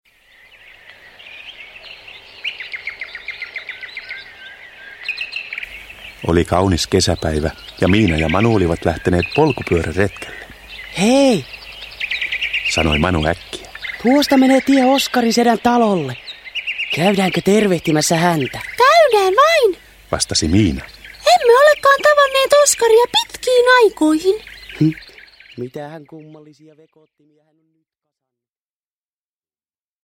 Miinan ja Manun ilmapallomatka – Ljudbok – Laddas ner